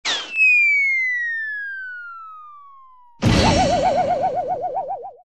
Звуки падения
Падение с высоты и сильный удар